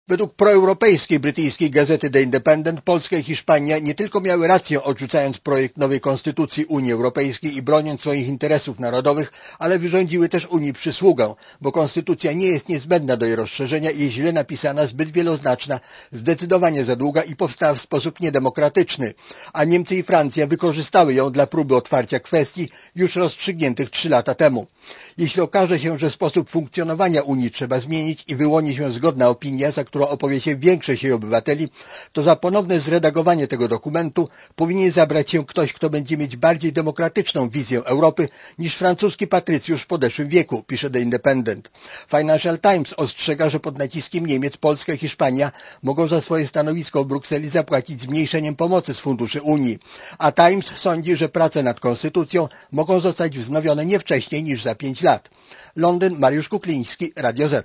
Korespondencja z Londynu (228kb)